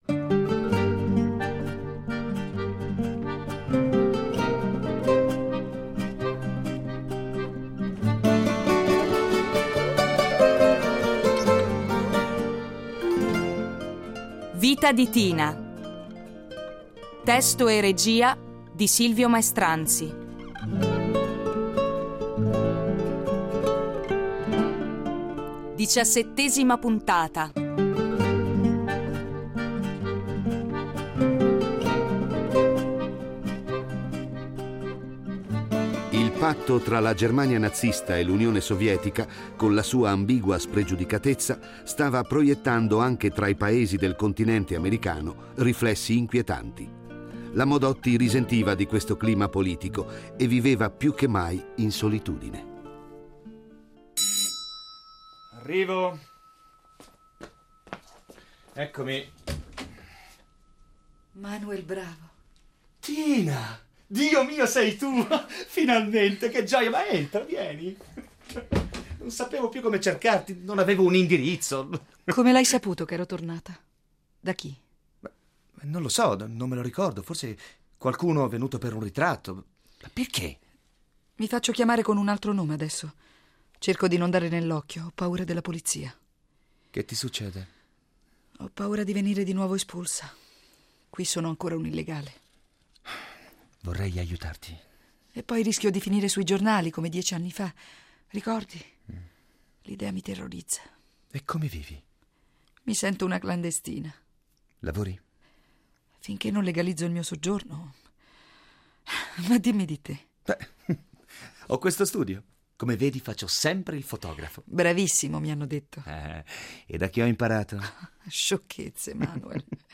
Originale radiofonico in 18 puntate